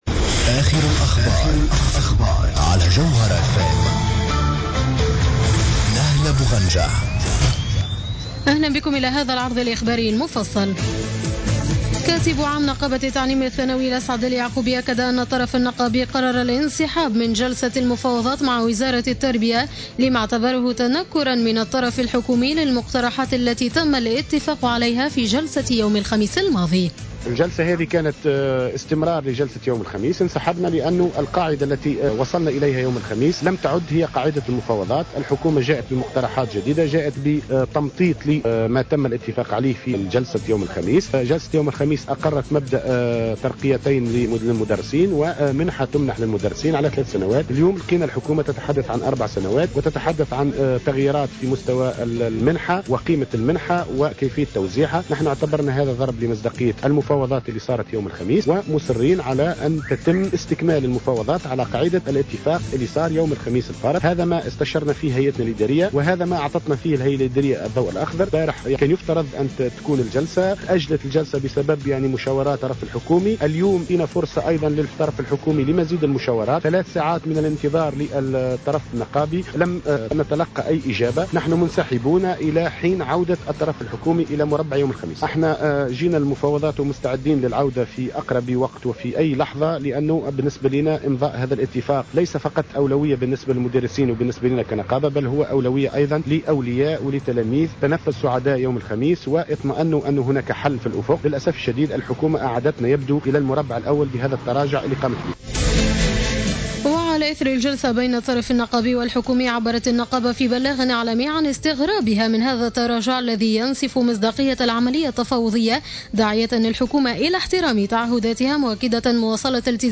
نشرة أخبار السابعة مساء ليوم الأحد 5 أفريل 2015